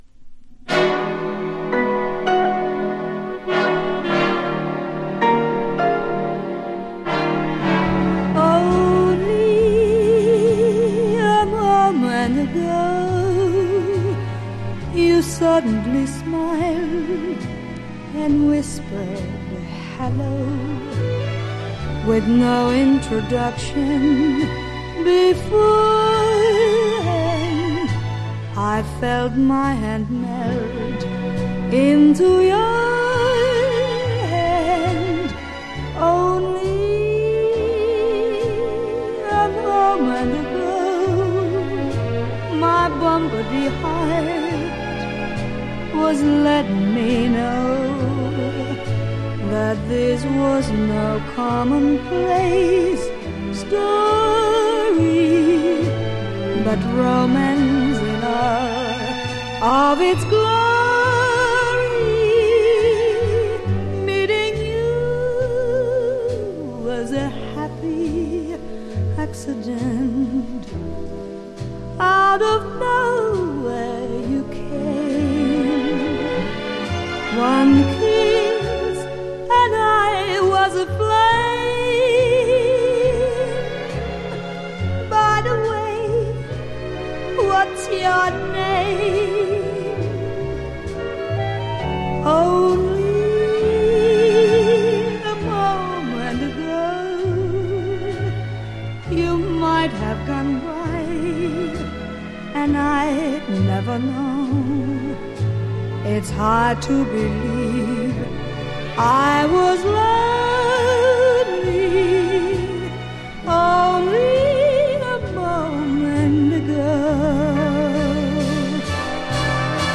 ストリングス